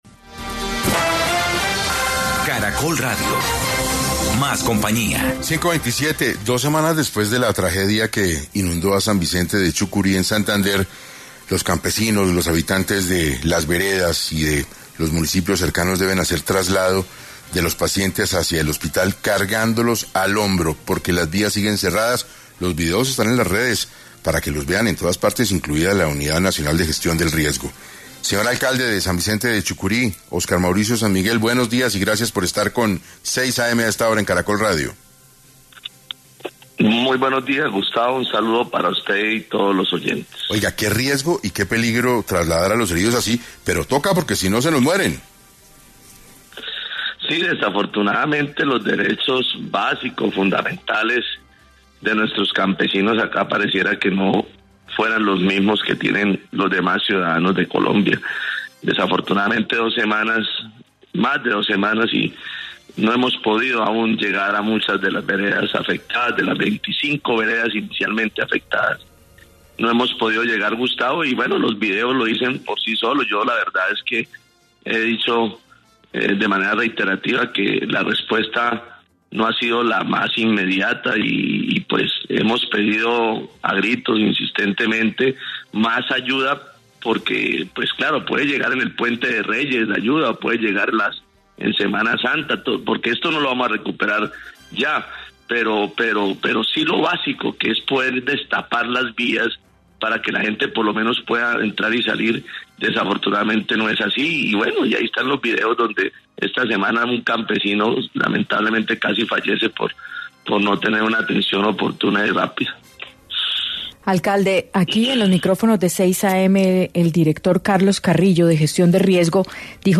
En conversación con 6 AM, el alcalde del pueblo, Óscar Sanmiguel habló sobre las afectaciones que ha tenido el municipio, y las complicaciones para remover escombros, descongestionar vías de acceso, y atender a los damnificados.